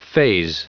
Prononciation du mot faze en anglais (fichier audio)
Prononciation du mot : faze